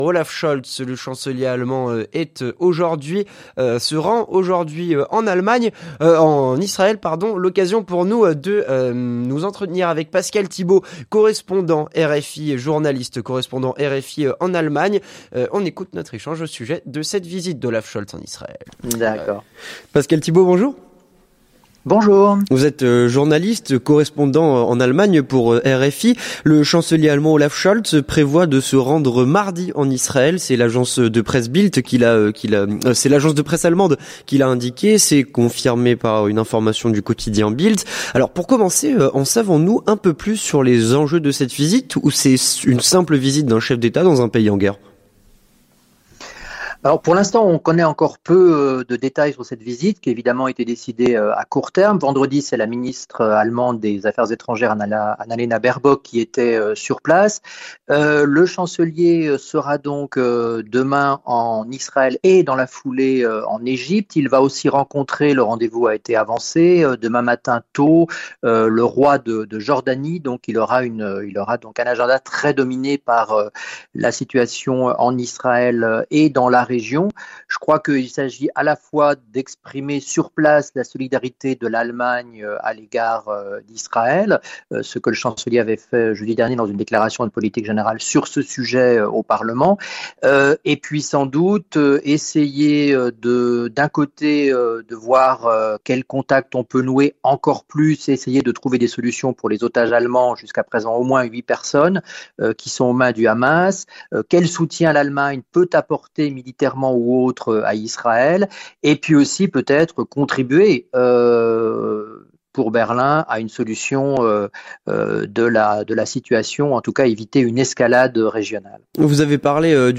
L'entretien du 18H - Olaf Scholz arrive en Israël.